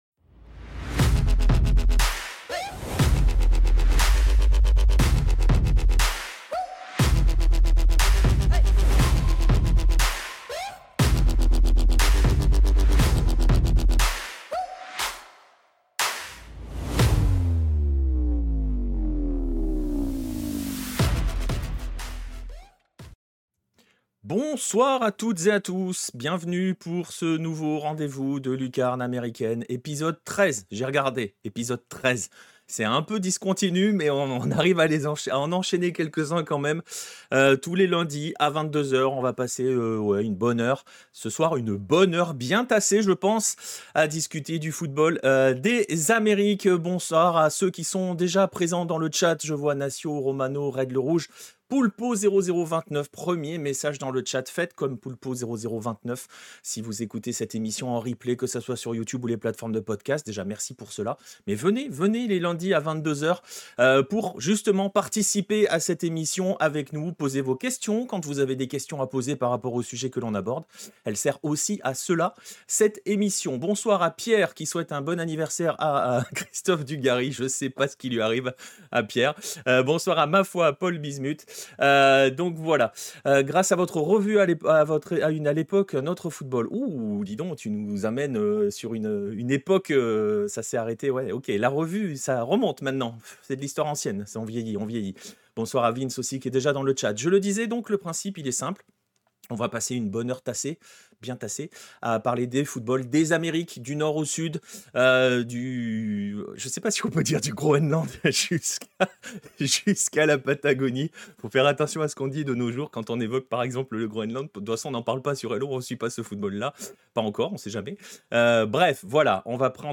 Electronic Future Bass